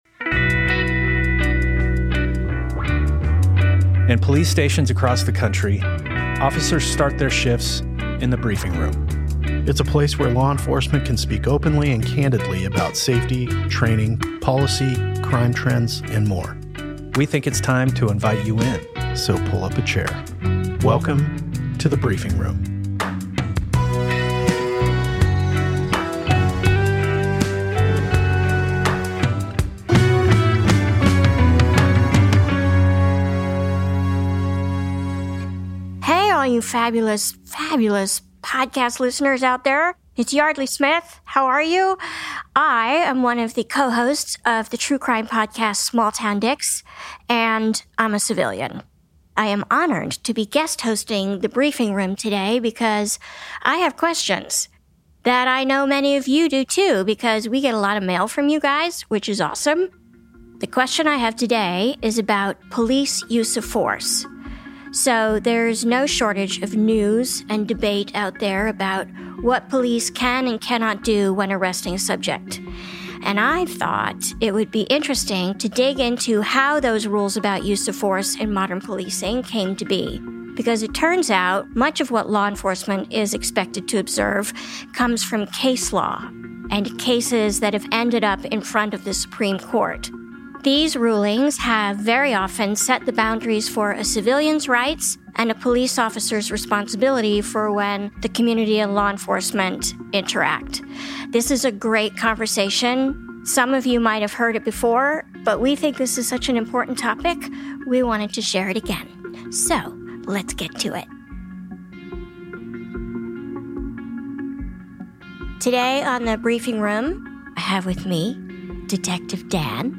In today's briefing, we bring back a discussion about two U.S. Supreme Court cases that define what police can and cannot do when they need to bring force to bear.